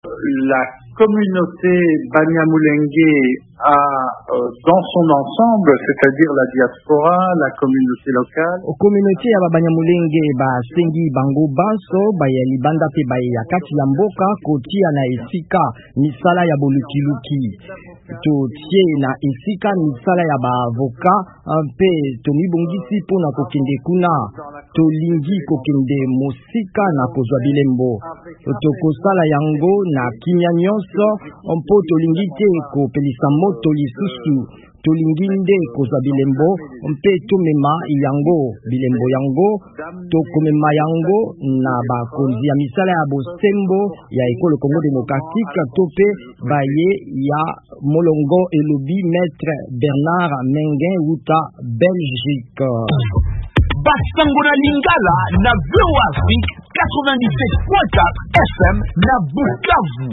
Communauté ya ba Banyamulenge ezwi ba avocats mpe esengi bango komema bobomami na Minembwe (Sud-Kivu) na bazuzi. VOA Lingala ebengaki na singa wuta Belgique